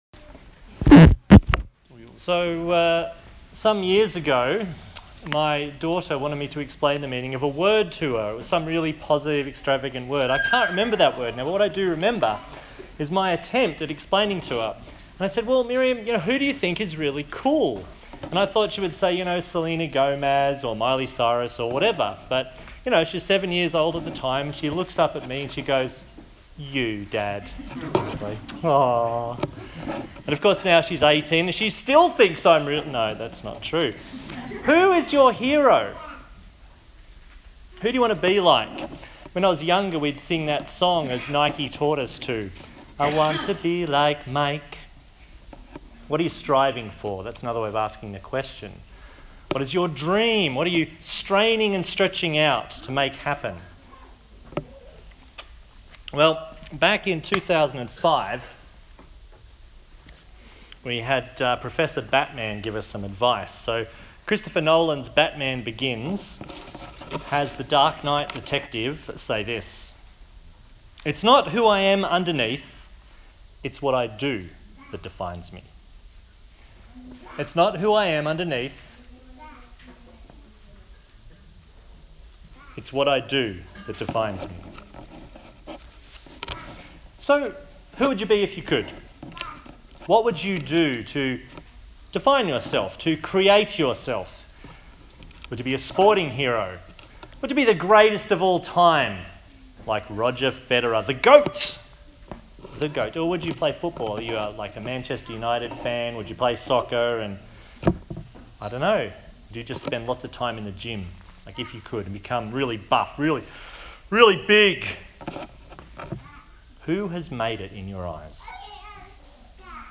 Philippians Passage: Philippians 3:1-14 Service Type: Sunday Morning A sermon on the book of Philippians